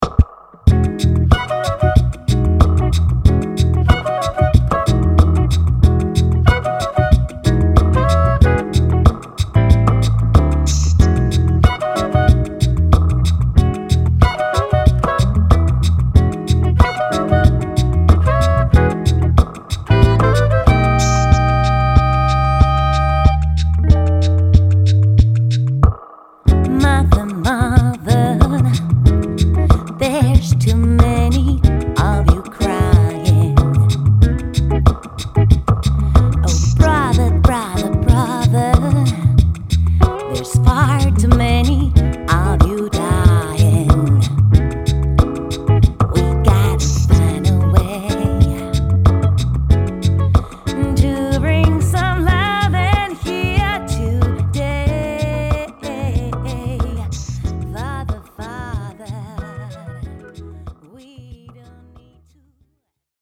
ソウル/ファンク/ジャズを軸にした
Saxophone,Flute
Bass & Loop
Guitar
ドラムレス編成ながら、ライブ・ルーピングを駆使し独自のグルーヴを紡ぎ出す。